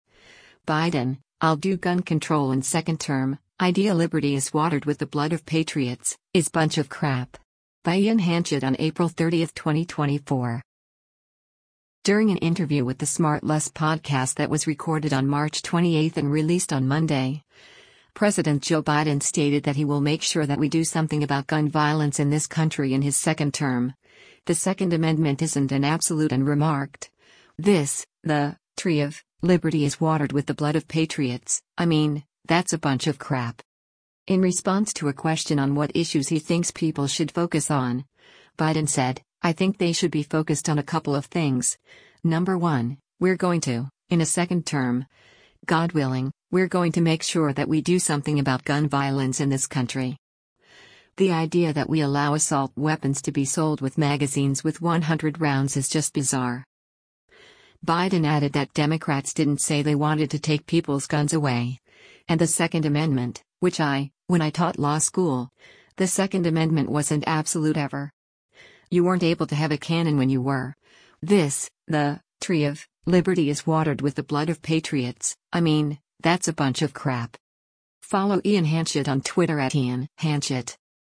During an interview with the “SmartLess” podcast that was recorded on March 28 and released on Monday, President Joe Biden stated that he will “make sure that we do something about gun violence in this country” in his second term, the 2nd Amendment isn’t an absolute and remarked, “this — the [tree of] liberty is watered with the blood of patriots, I mean, that’s a bunch of crap.”